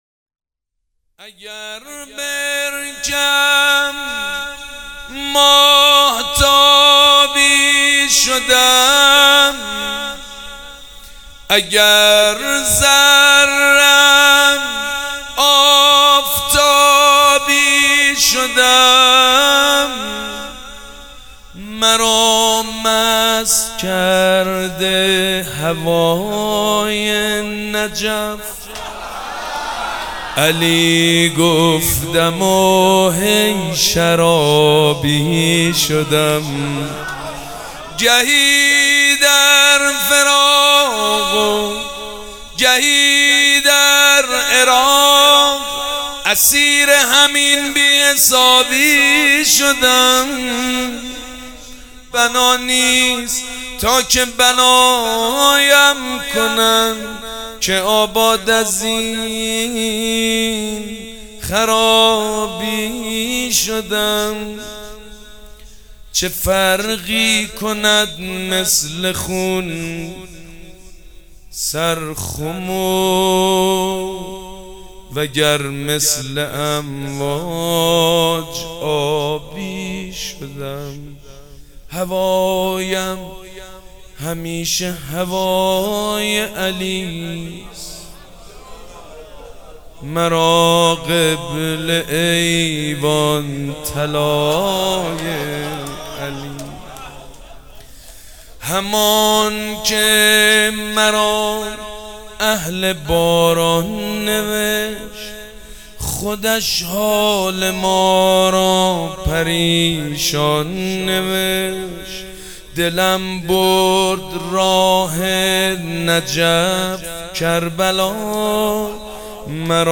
شعرخوانی
سبک اثــر شعر خوانی
جشن نیمه شعبان